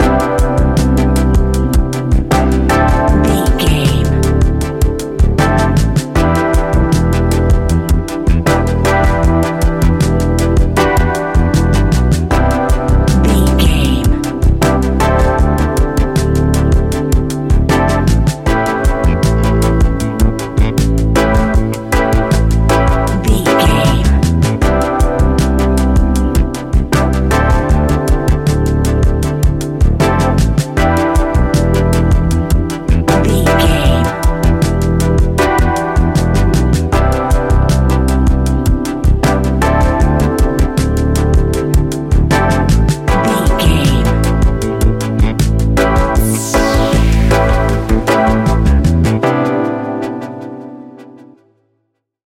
Ionian/Major
A♭
laid back
sparse
new age
chilled electronica
ambient